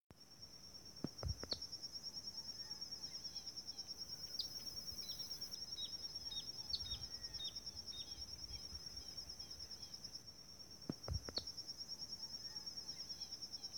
Rufous-sided Crake (Laterallus melanophaius)
Contacto pocas veces escuchado.
Sex: Both
Province / Department: Entre Ríos
Condition: Wild
Certainty: Observed, Recorded vocal